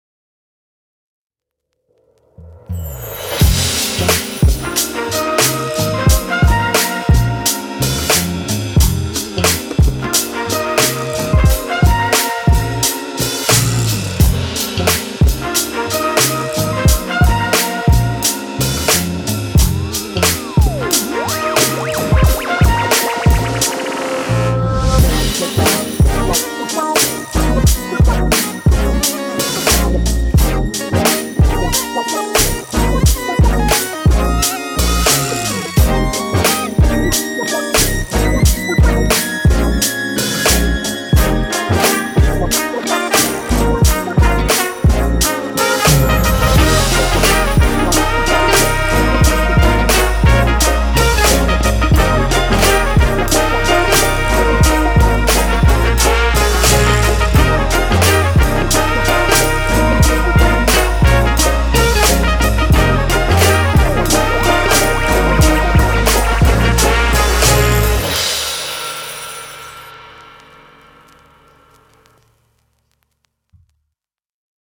音色试听
嘻哈采样包